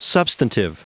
Prononciation du mot substantive en anglais (fichier audio)
Prononciation du mot : substantive